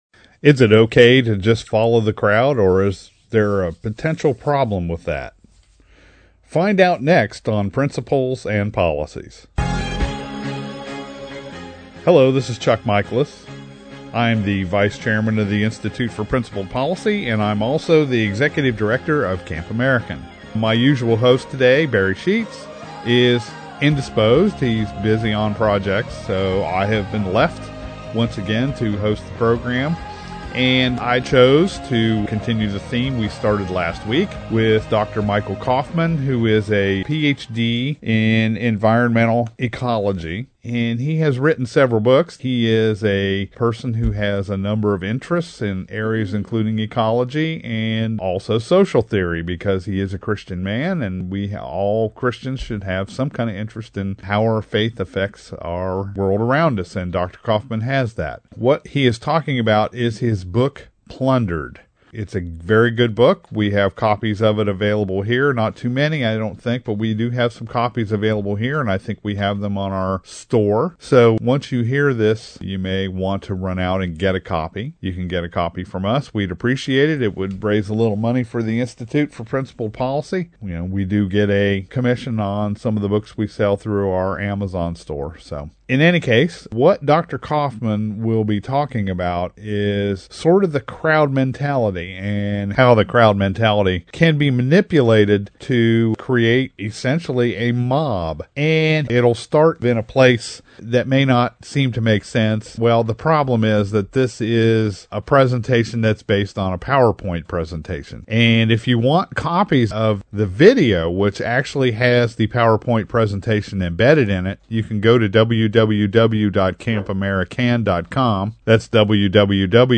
Our Principles and Policies radio show for Saturday April 4, 2015.